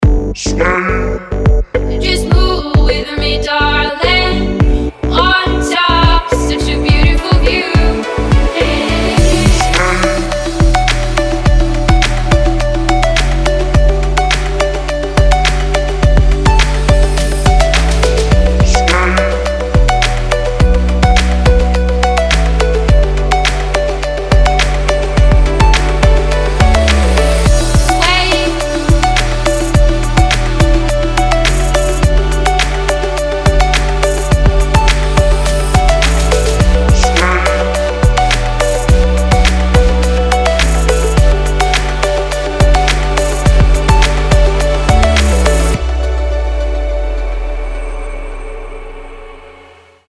• Качество: 320, Stereo
Смесь Стилей Future Bass и Trap